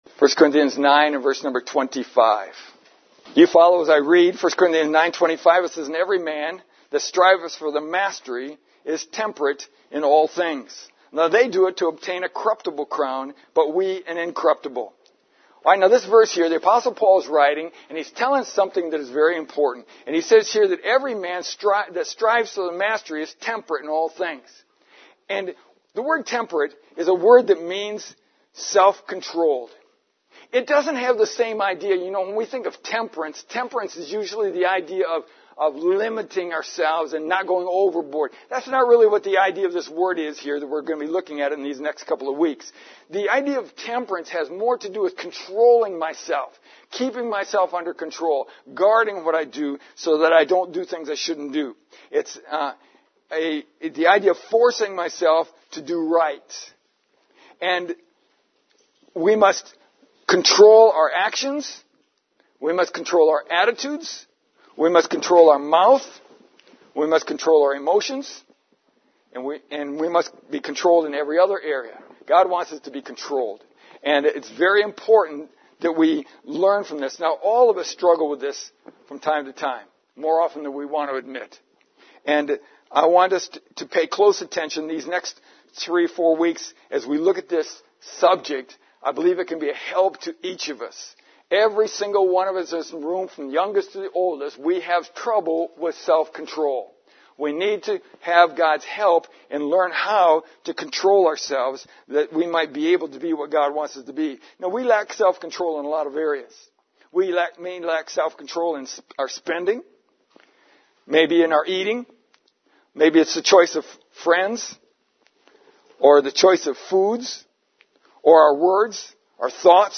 In the first sermon of this short series, I want to identify three reasons that self-control is preparatory to winning in the Christian life.